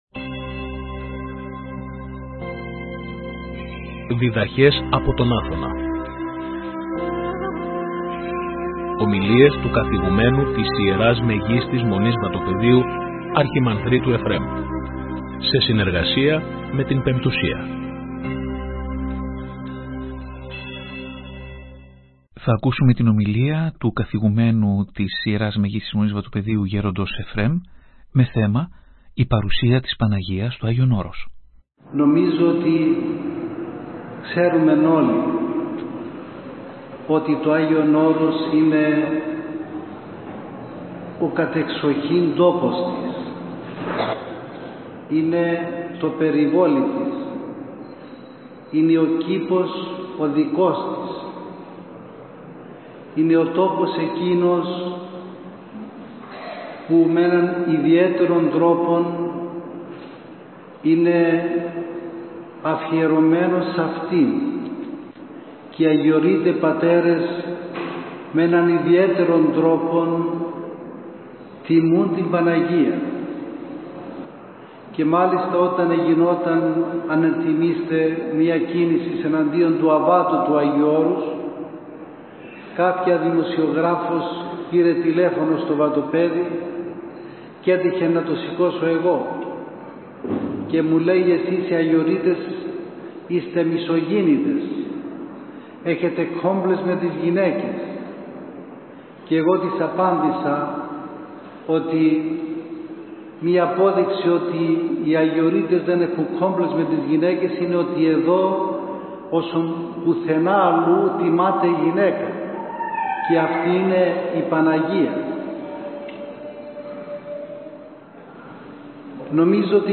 Η ομιλία μεταδόθηκε και από την εκπομπή «Διδαχές από τον Άθωνα» στη συχνότητα του Ραδιοφωνικού Σταθμού της Πειραϊκής Εκκλησίας την Κυριακή 24 Σεπτεμβρίου 2023.